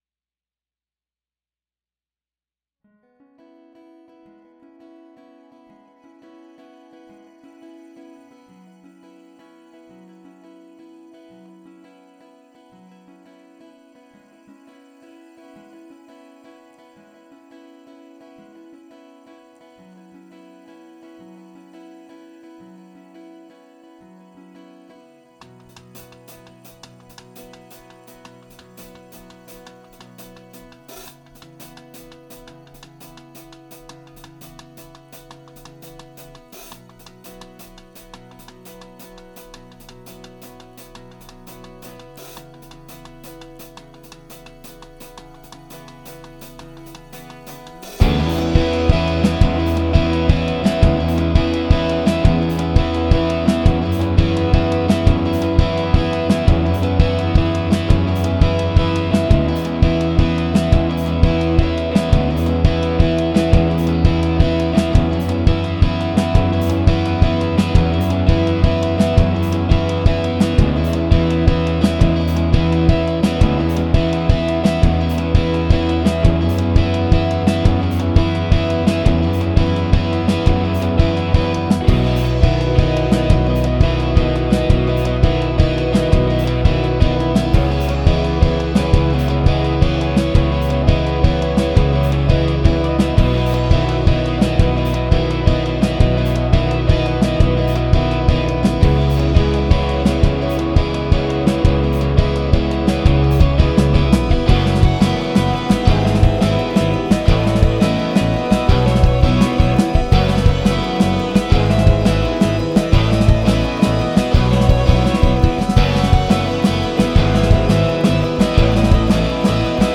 la versión sin voz.